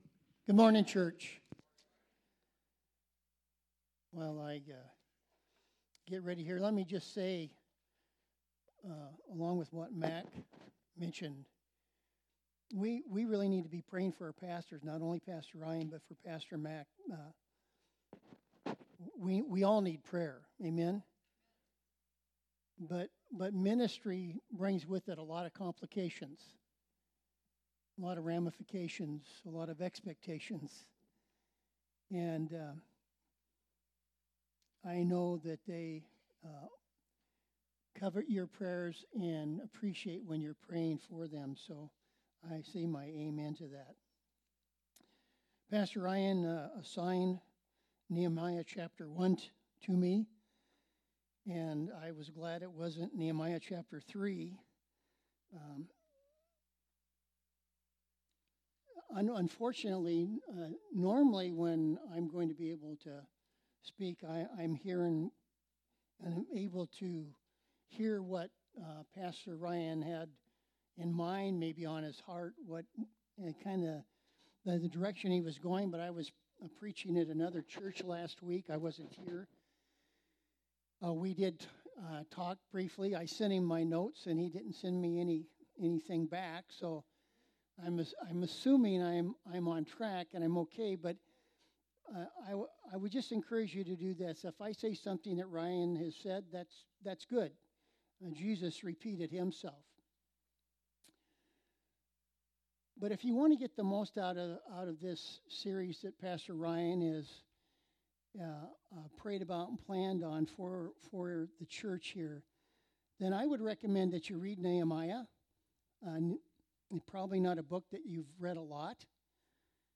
Audio Sermons | Tonasket Free Methodist Church